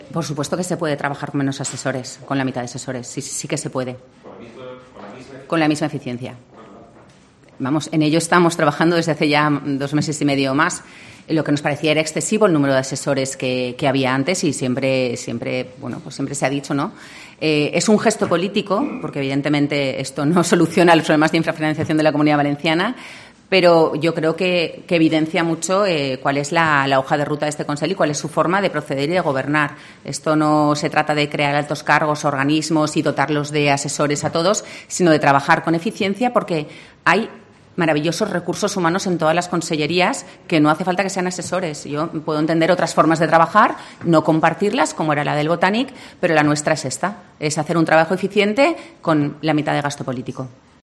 La portavoz del Consell, Ruth Merino, explica el valor del gesto de reducir asesores